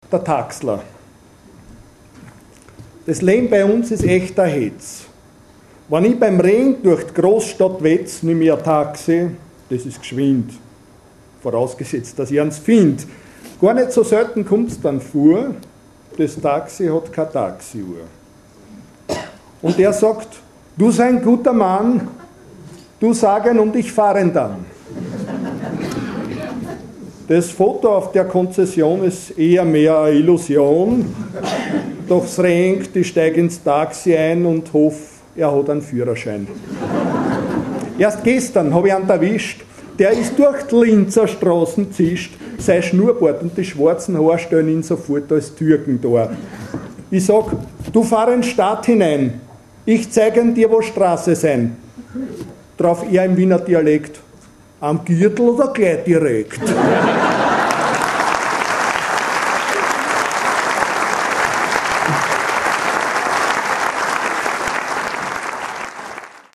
lassen Sie sich von unserem Trio „Tempo di Valse“ mit beliebten Wiener Melodien verzaubern und genießen Sie Wiener Schmäh mit Wiener Charme!